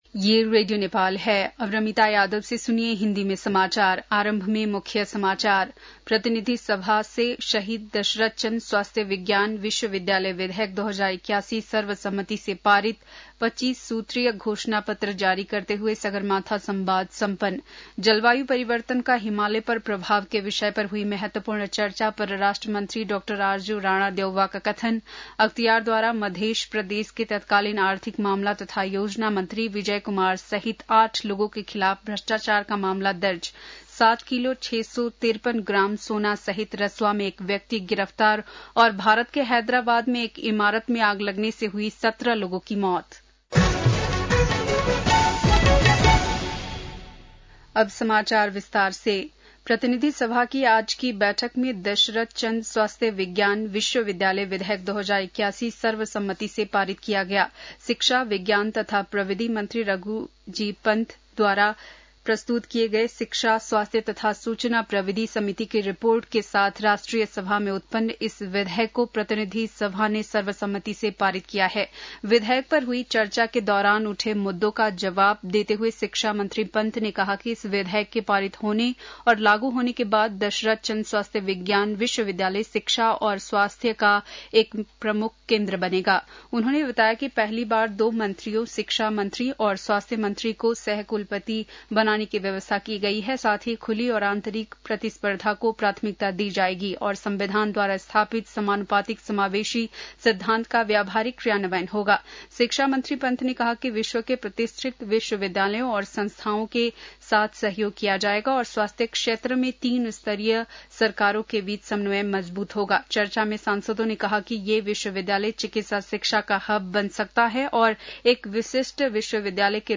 बेलुकी १० बजेको हिन्दी समाचार : ४ जेठ , २०८२
10-pm-hindi-news-1.mp3